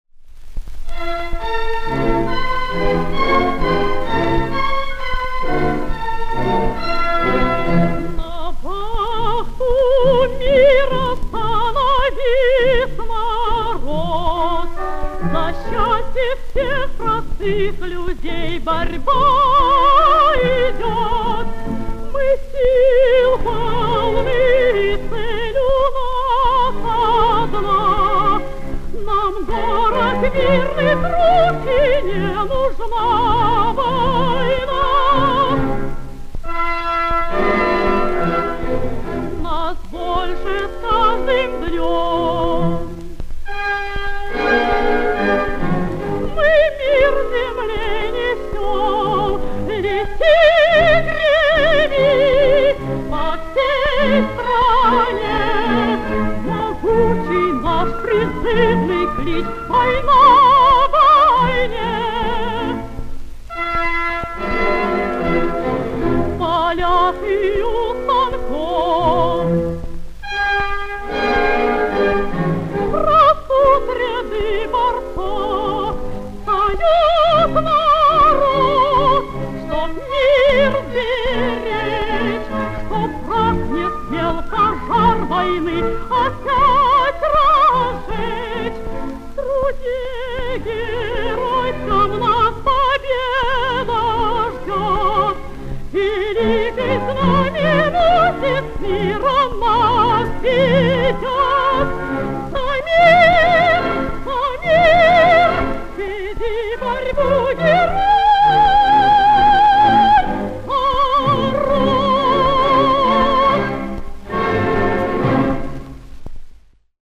Героическое ариозо в исполнении выдающейся солистки Радио.